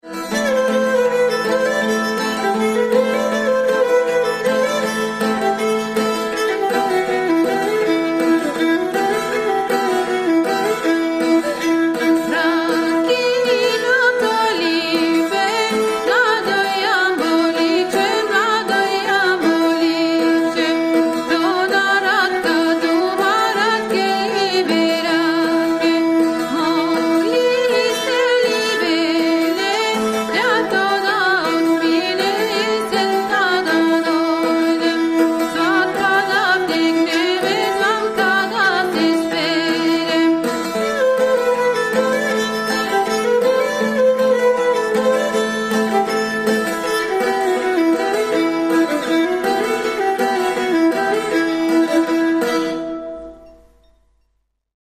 Tsârvuli are an Edinburgh-based group specialising in Bulgarian and Macedonian folk music
played on traditional instruments, with a particular emphasis on dance music.
gadulka, tapan, vocals
Kaval, tapan
Gajda, tapan, tambura
Bulgarian